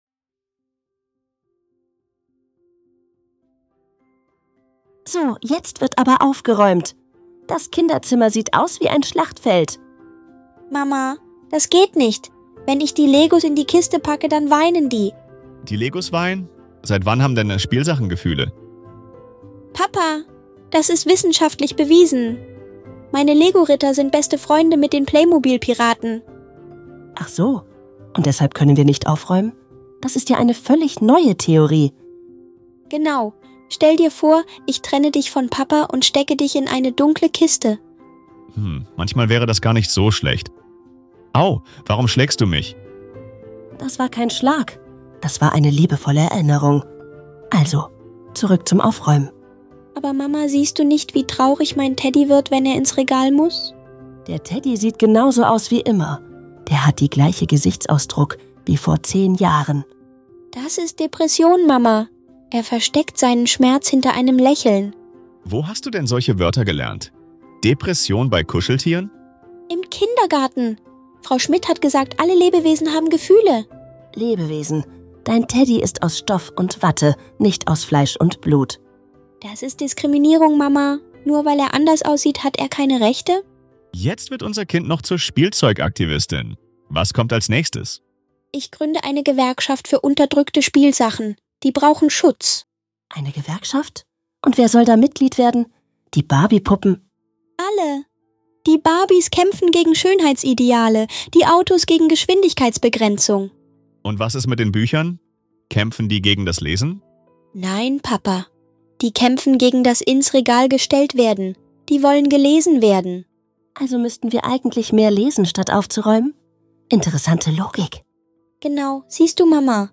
In diesem lustigen Dialog erfahrt ihr, wie aus einem normalen